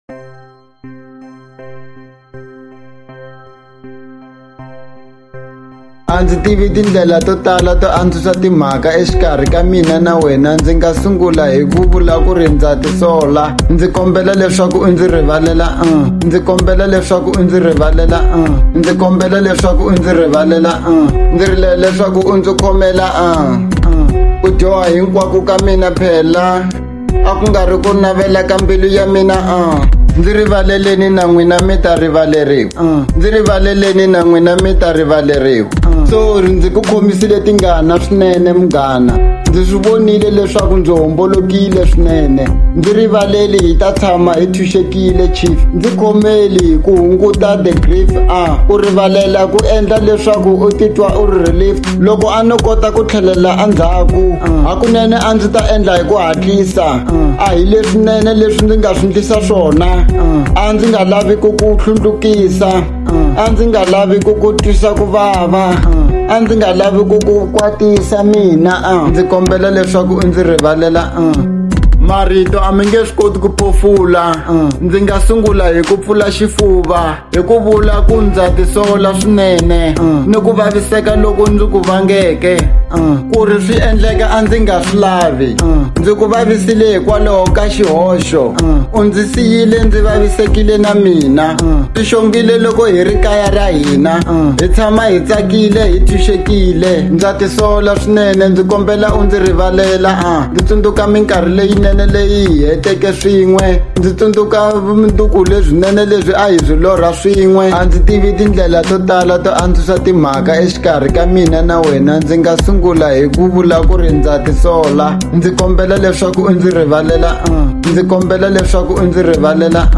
02:36 Genre : Hip Hop Size